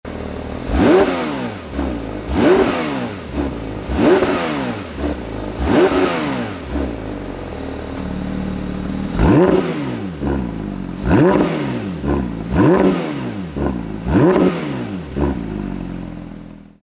Listen to it's sweet symphony
• 4.0 Litre Flat-6 Engine with Dry-Sump: 510ps/470nm
PUV-GT3-revs.mp3